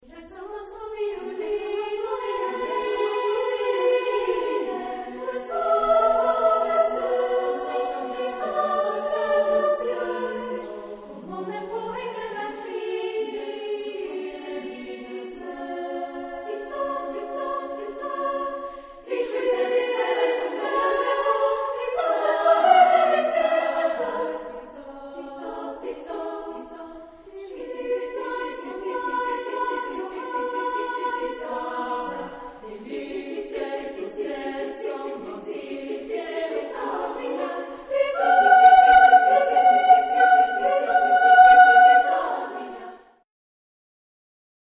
Genre-Style-Forme : Madrigal ; Profane
Type de choeur : SAA  (3 voix égales de femmes )
Tonalité : mi majeur
Consultable sous : 20ème Profane Acappella